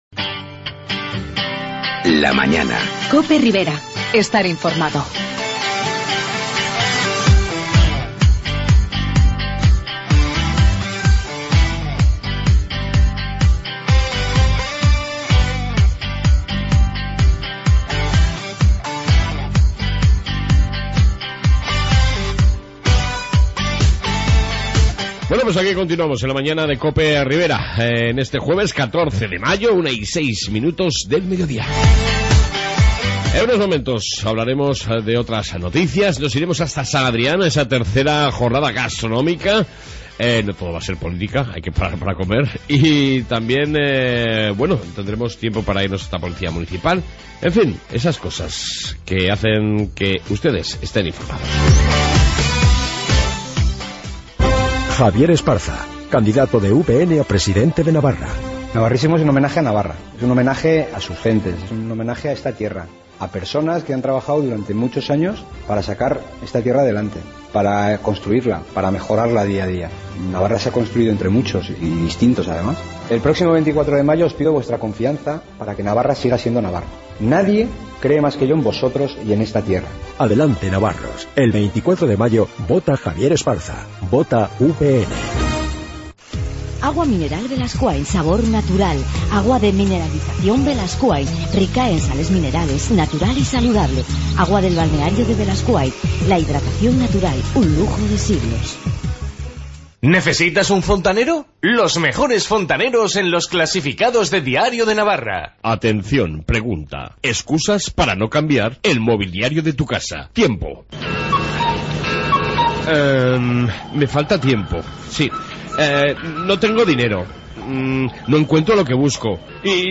AUDIO: Información pol Municipal, Noticias y entrevista sobre las III jornadas gastronomicas de San Adrian